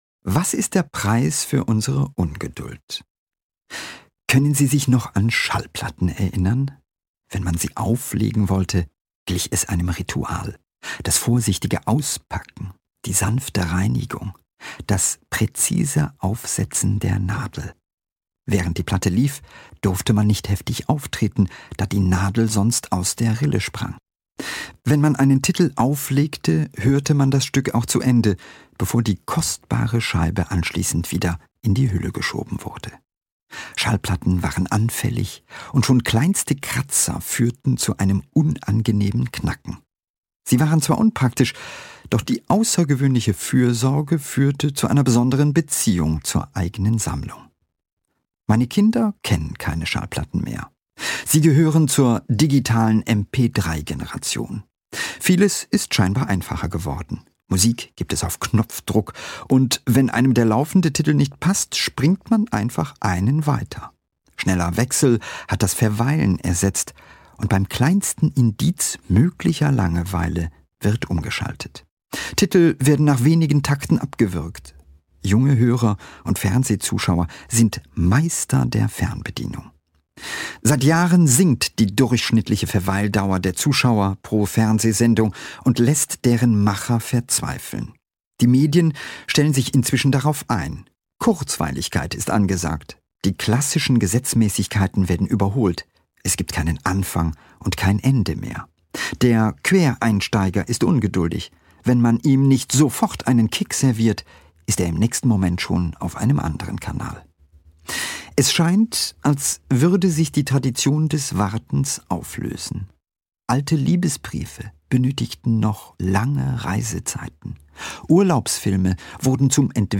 Ranga Yogeshwar (Sprecher)